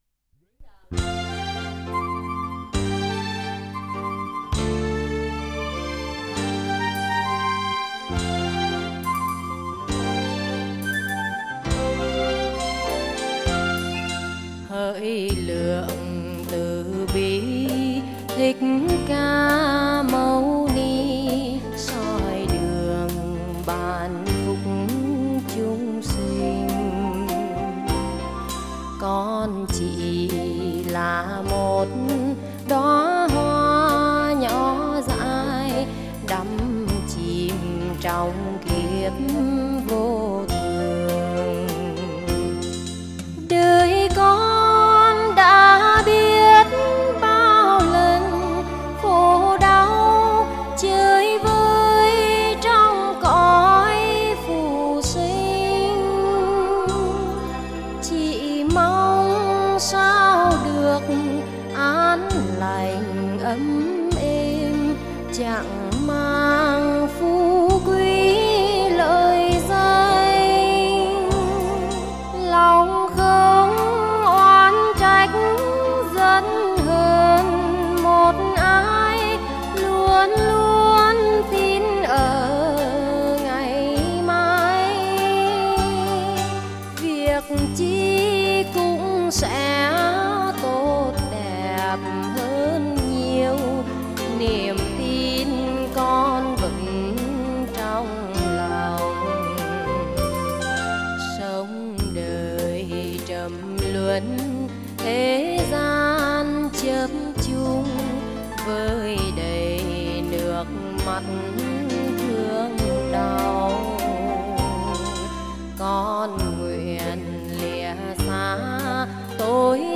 Category: Tân Nhạc